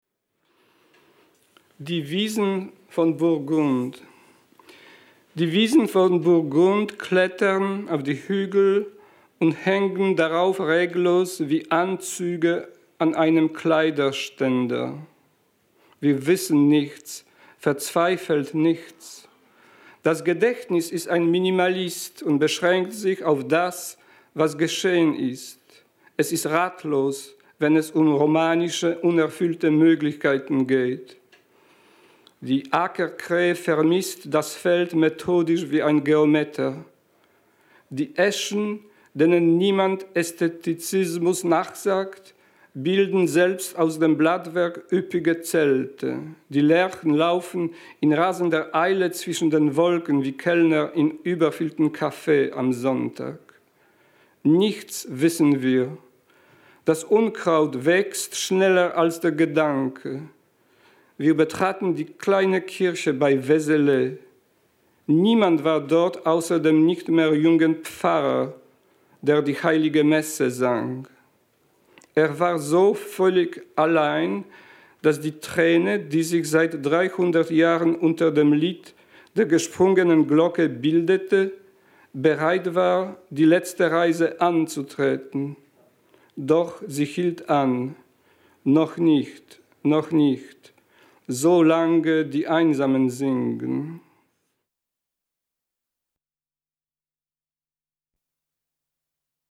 Lesung von Adam Zagajewski in der literaturWERKstatt Berlin zur Sommernacht der Lyrik – Gedichte von heute